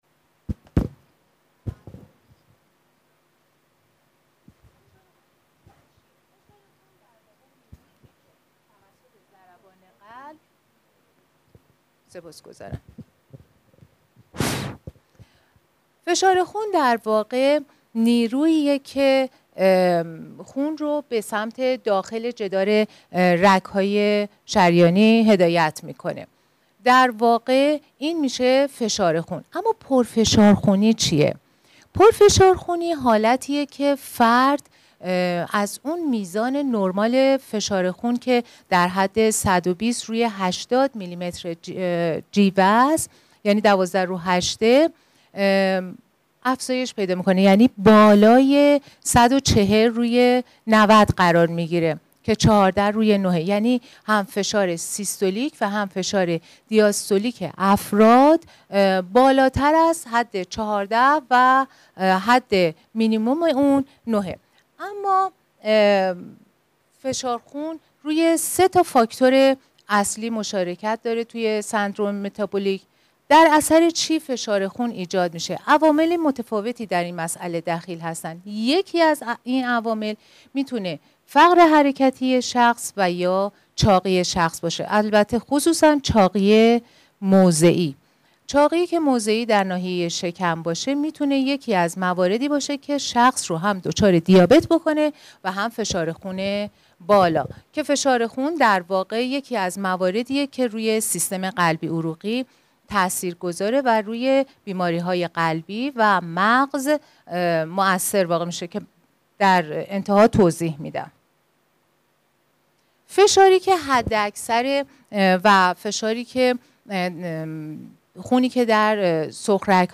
سخنرانی
مکان: سالن حکمت